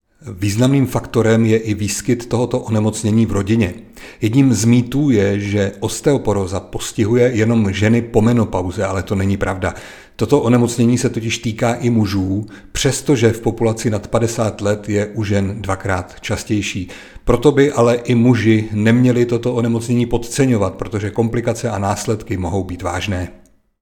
Tisková zpráva